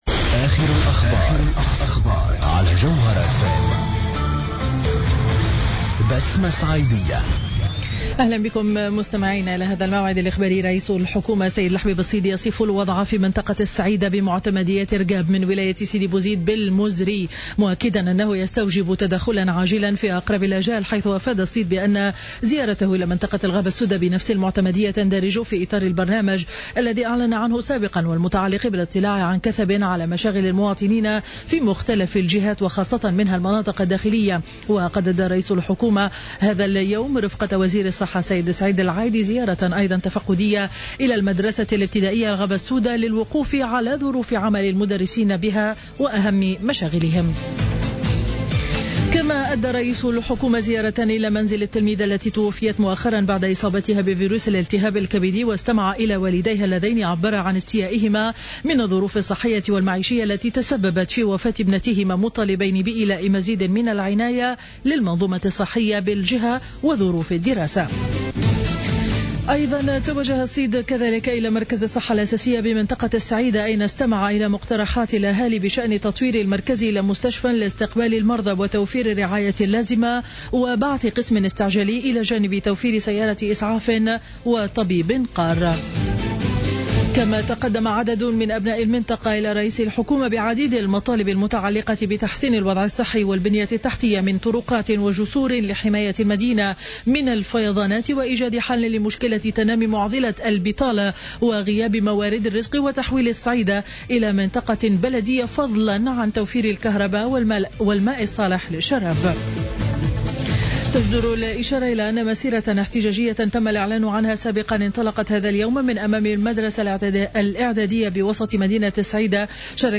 نشرة أخبار منتصف النهار ليوم الثلاثاء 17 فيفري 2015